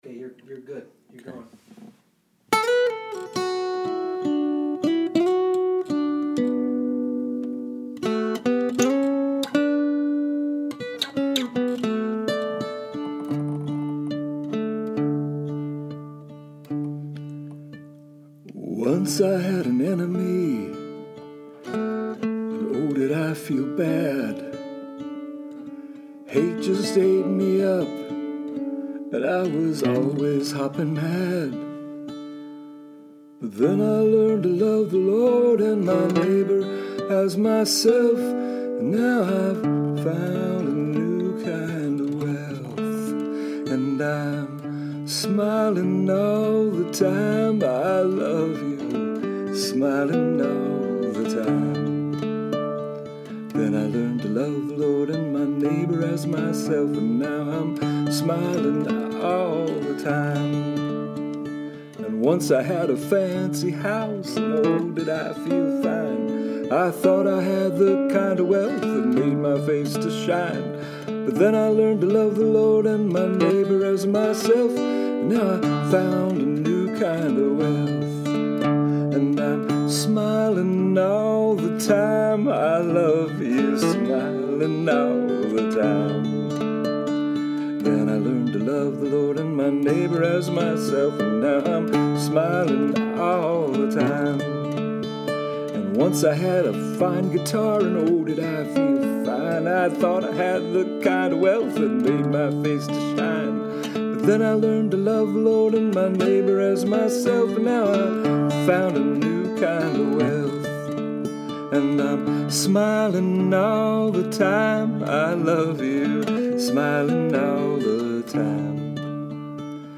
One is called “Smilin’ All the Time” and provides one of the old time camp revival elements of the piece.